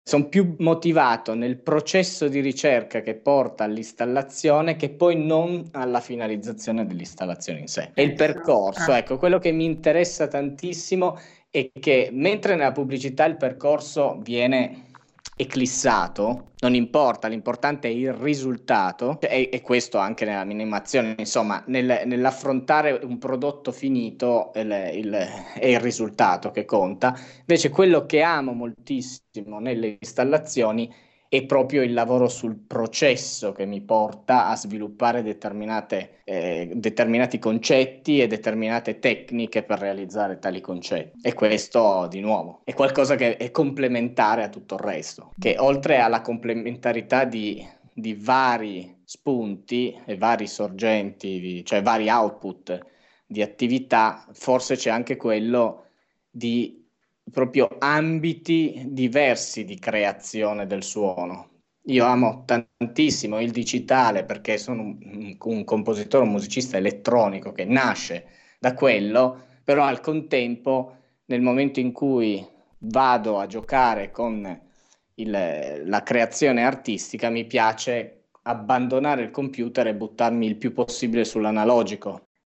1-sound-design-shorter.mp3